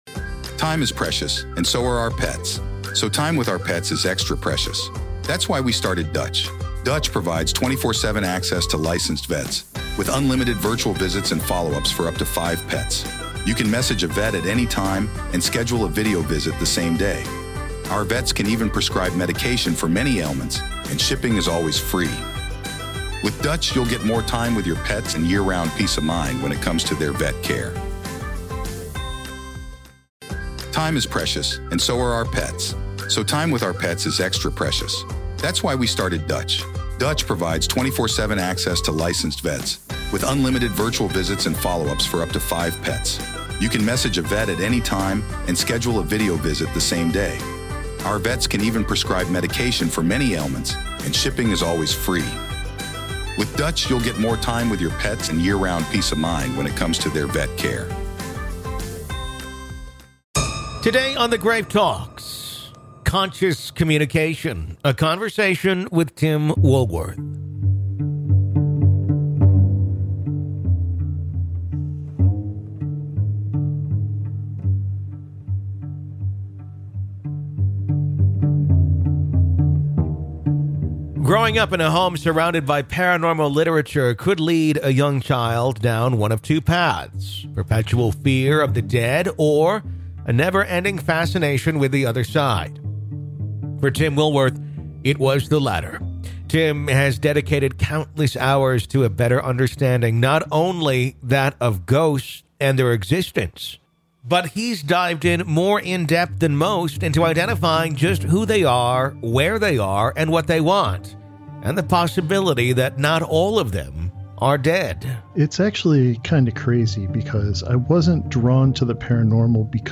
If you're enjoying our interviews and conversations about "The Dead", why not listen ad-free?